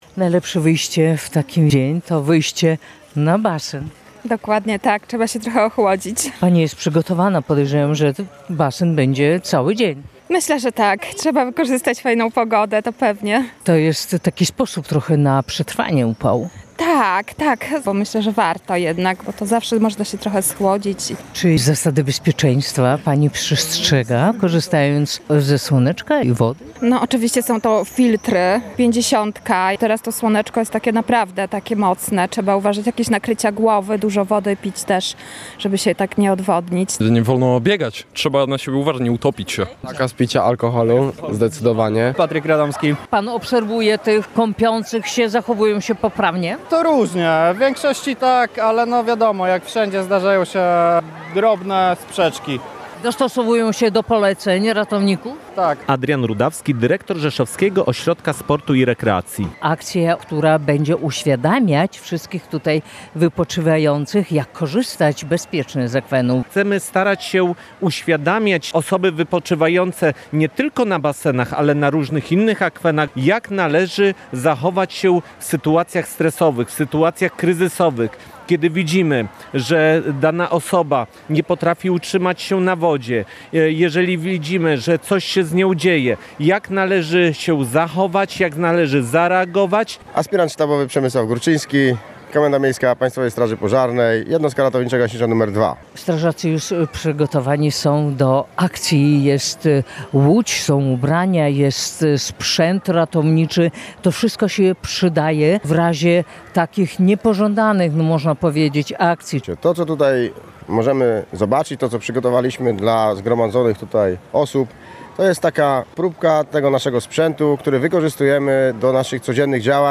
O zasadach bezpiecznego wypoczynku nad wodą przypominali ratownicy WOPR, strażacy i medycy.